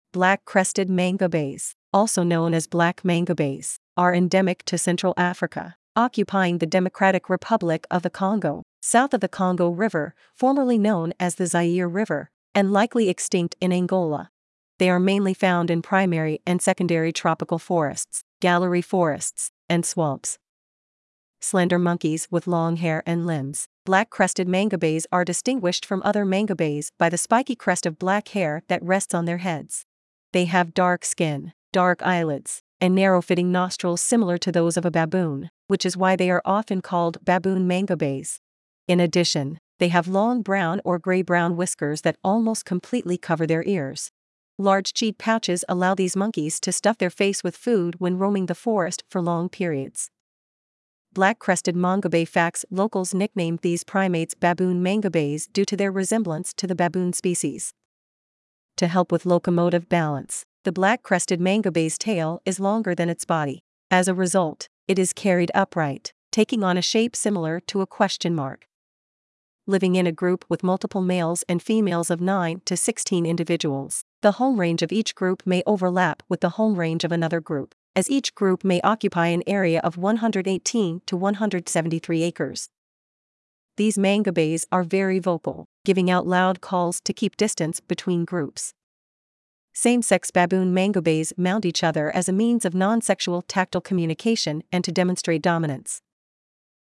Black-crested mangabey
• These mangabeys are very vocal, giving out loud calls to keep distance between groups.
Black-crested-mangabey.mp3